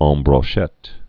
ɴ brô-shĕt)